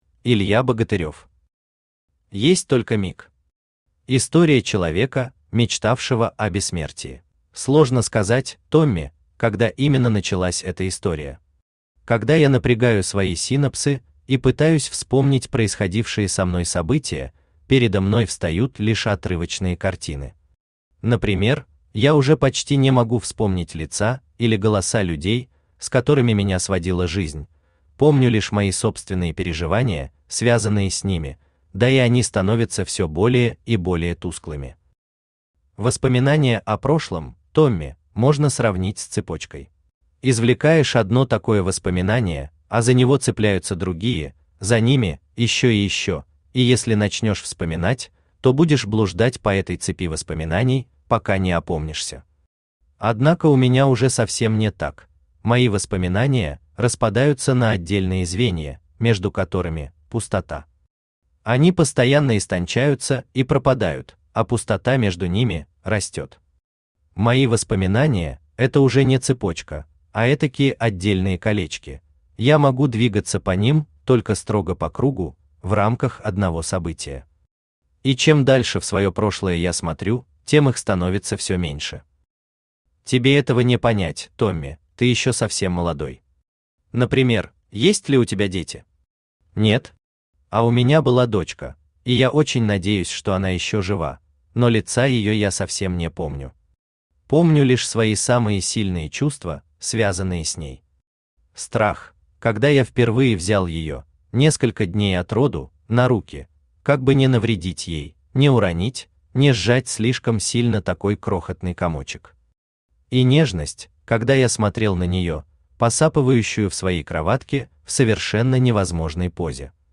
Аудиокнига Есть только миг. История человека, мечтавшего о бессмертии | Библиотека аудиокниг
История человека, мечтавшего о бессмертии Автор Илья Богатырев Читает аудиокнигу Авточтец ЛитРес.